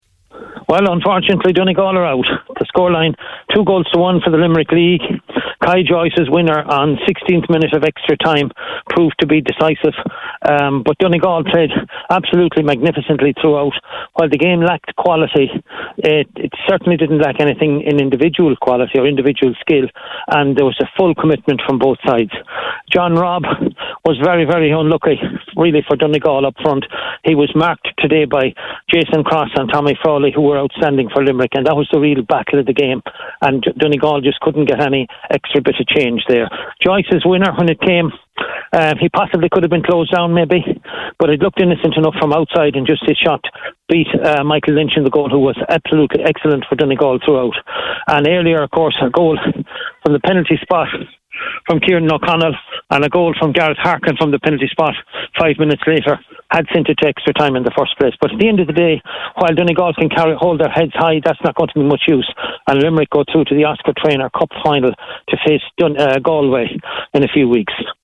live at full time for Highland Radio Sunday Sport…